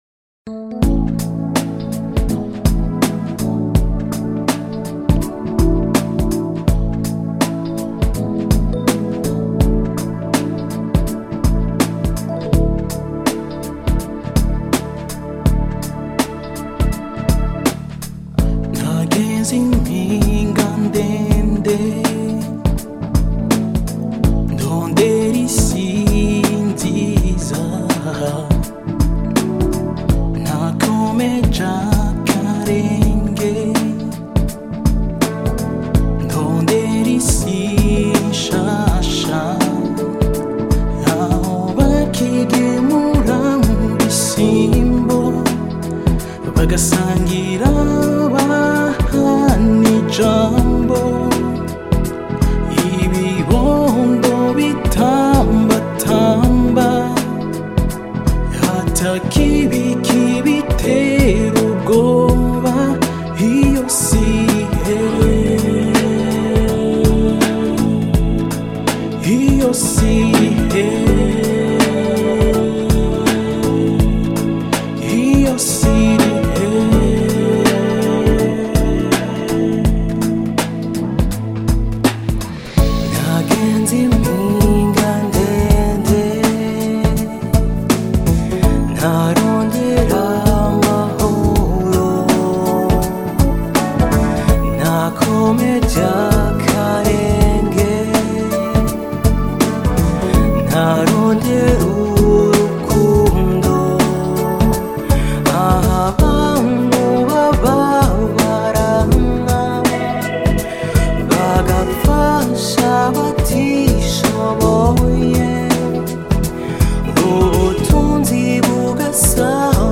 Umurwi w’abaririmvyi